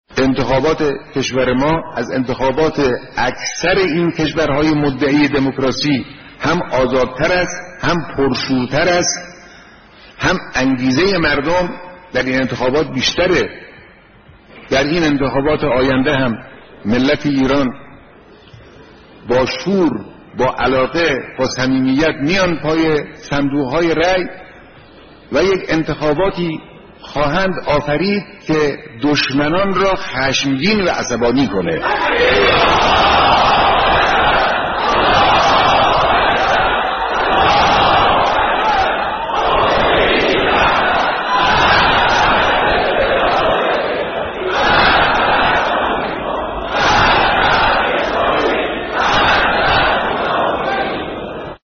حضور مردم در انتخابات / گزیده‌ای از بیانات رهبر انقلاب در دیدار کارگران، پرستاران و معلمان